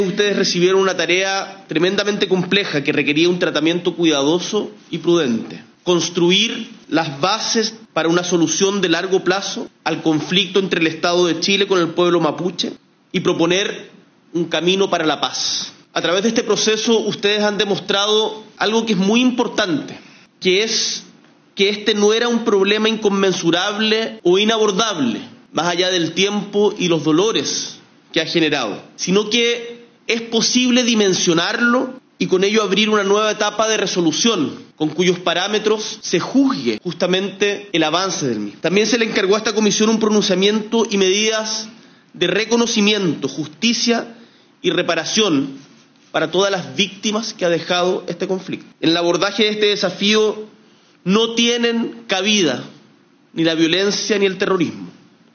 El evento, realizado en el Palacio de La Moneda, contó con la participación de los ministros del Interior, Álvaro Elizalde; Hacienda, Mario Marcel; Secretaría General de la Presidencia, Macarena Lobos; Desarrollo Social y Familia, Javiera Toro; y Agricultura, Esteban Valenzuela.